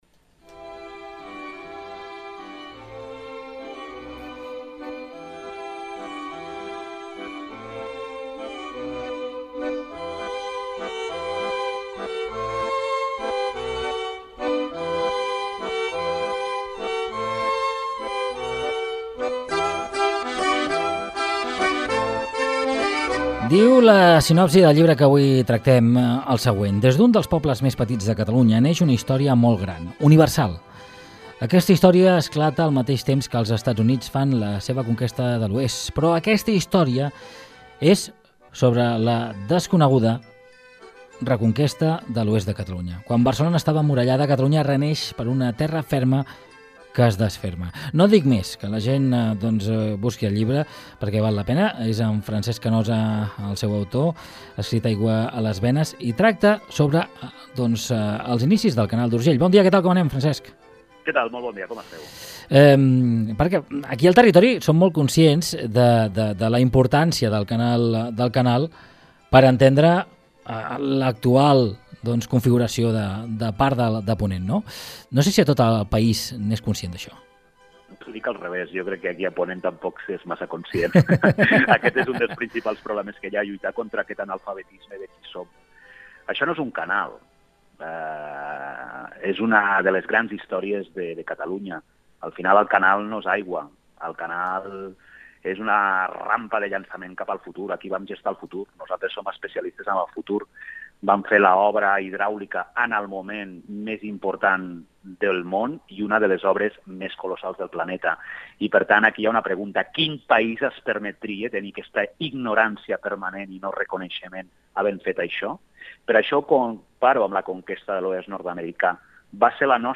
Entrevista
Gènere radiofònic Info-entreteniment